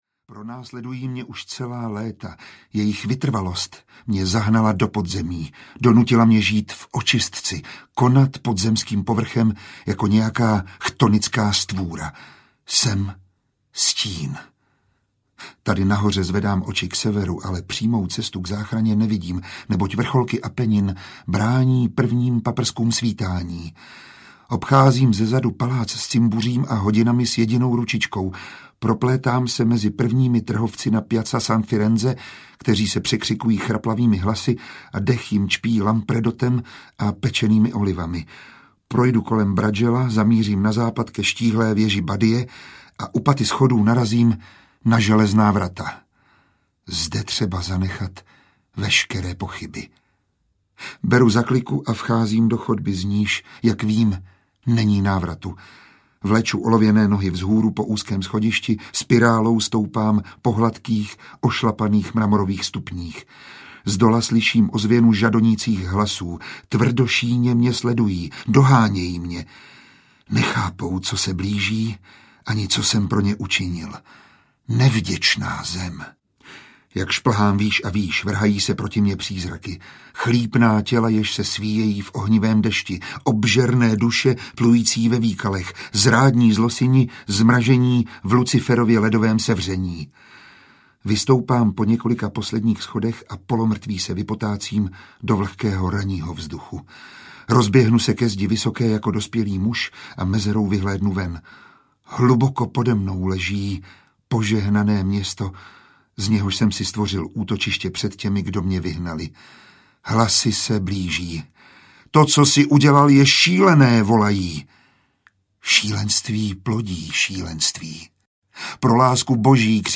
Inferno audiokniha
Ukázka z knihy
• InterpretMiroslav Táborský